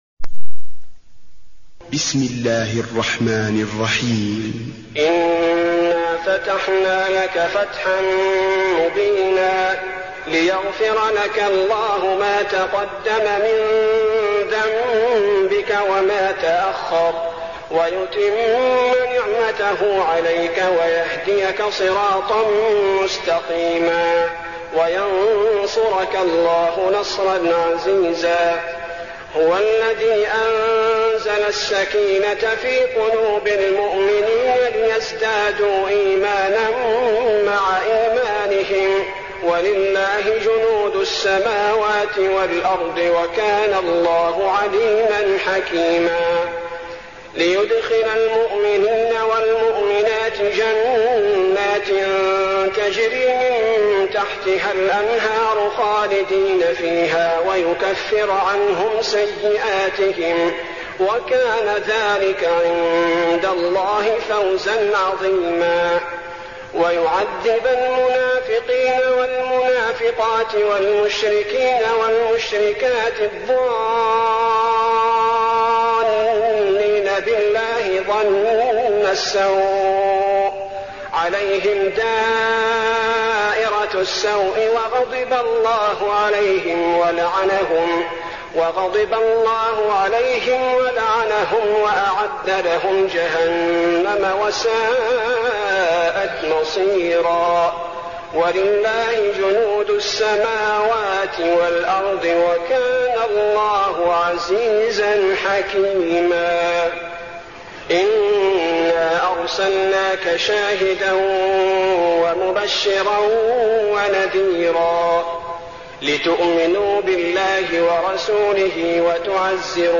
المكان: المسجد النبوي الفتح